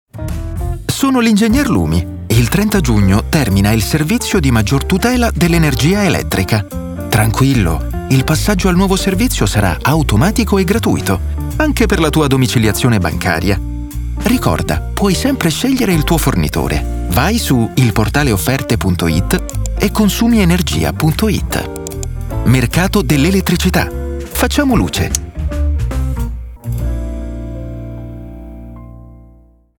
Gli spot radio